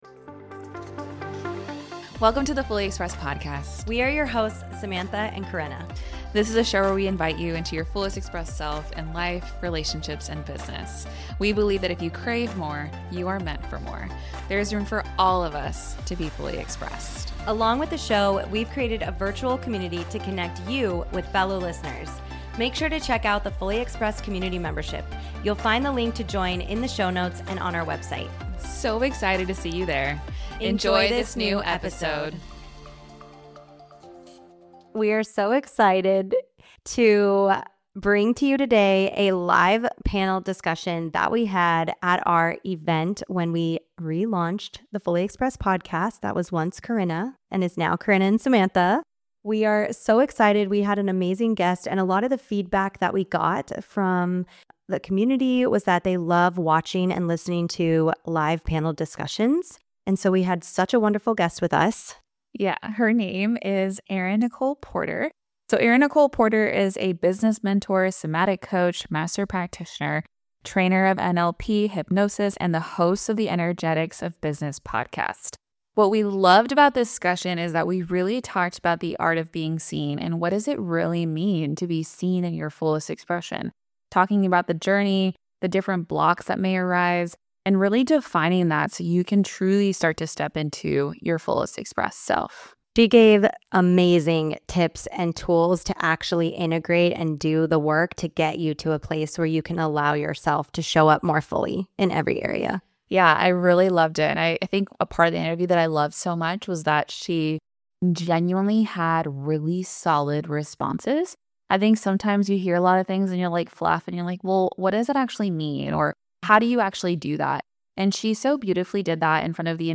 This Q&A episode is from an upcoming deep-dive that you don’t want to miss.